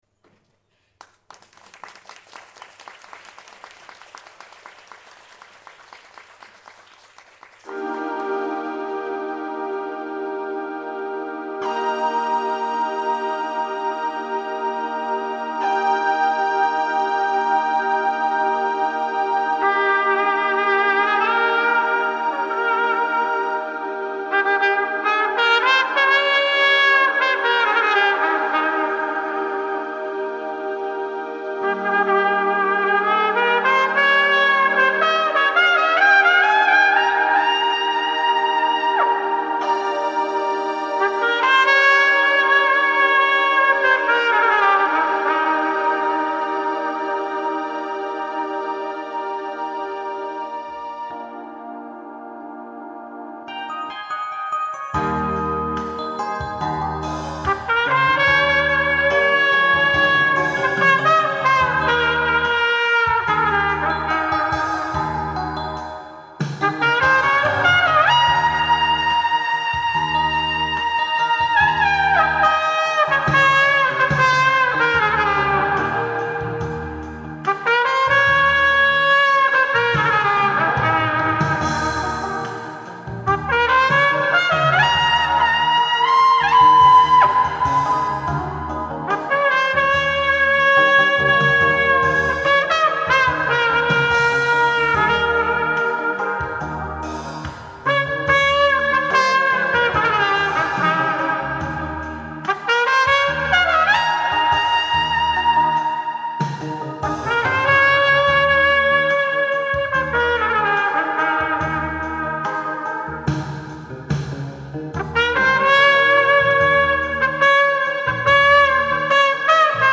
休憩を挟んで、二部の始まりです。
ちょっと残念なのは、油断してしまい、休憩中に楽器が冷えてしまって、立ち上がりの調子がイマイチだったこと。
高音をはずしたりしています。
主旋律に入ったあたりからだいぶ調子が戻ってきますが・・まあご愛嬌ということで、ご勘弁ください。